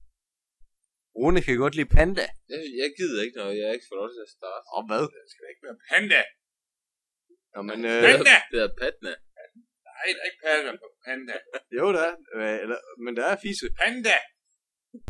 Et interview med en lille panda
altimens han forsøger at få en panda i tale.